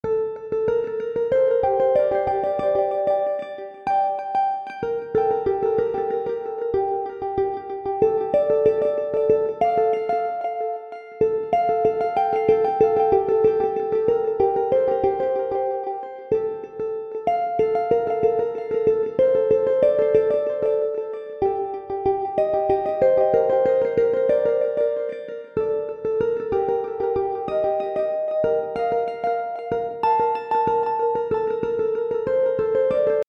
Solo (harmonique) de guitare
Elle passe ensuite dans l'arpège avec une vitesse de une notes toutes les croches (Demi-temps)
Quelques notes sont filtrées aléatoirement (vélocité) puis elle passe dans un écho avec un délai de une croche pointée ( 3/4 de temps )